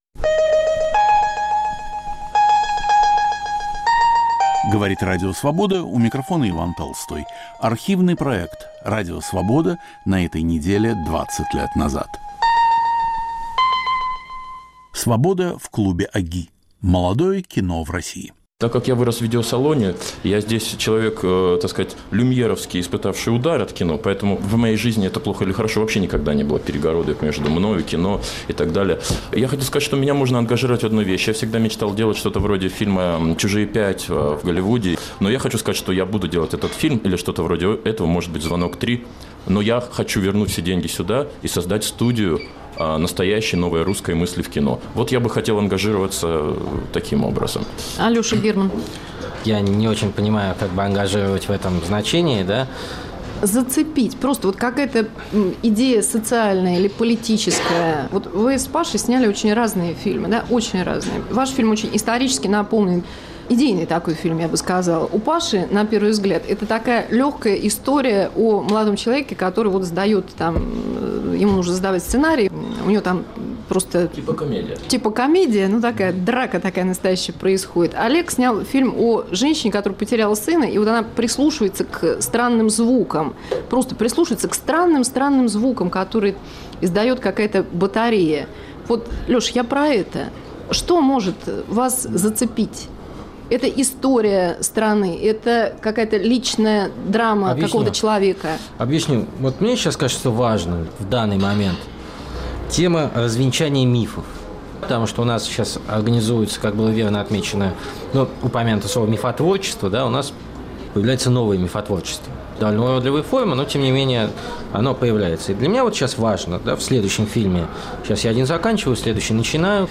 "Свобода" в клубе ОГИ. Молодое кино России